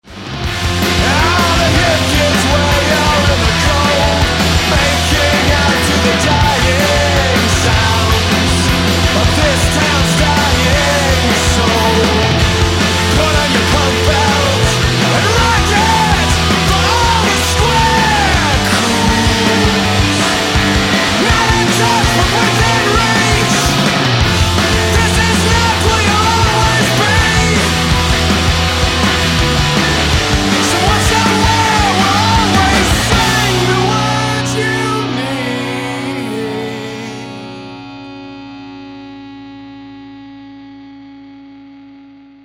It's like The Who meets Guided by Voices.